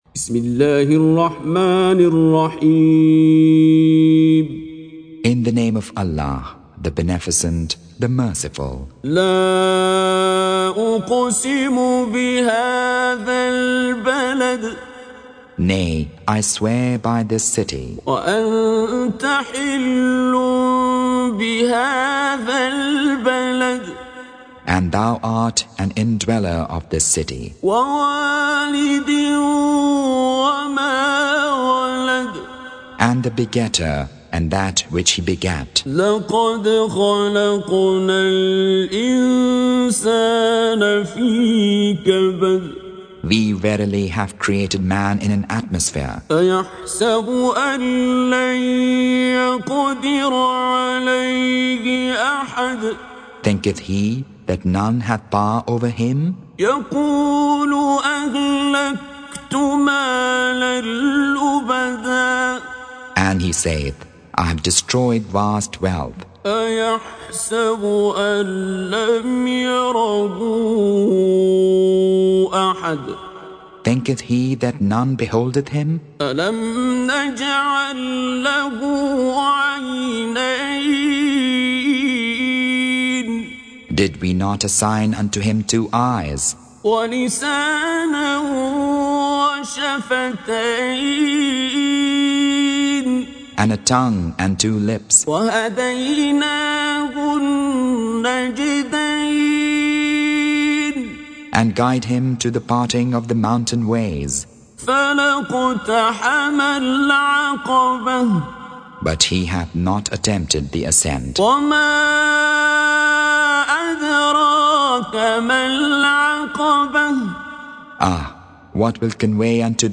Surah Sequence تتابع السورة Download Surah حمّل السورة Reciting Mutarjamah Translation Audio for 90. Surah Al-Balad سورة البلد N.B *Surah Includes Al-Basmalah Reciters Sequents تتابع التلاوات Reciters Repeats تكرار التلاوات